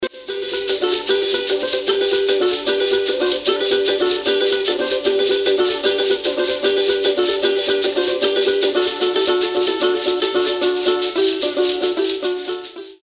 Le Ndzendze
Le ndzendze est une forme de valiha originaire des Comores et dont le son est proche de la kora.
ndzendze.mp3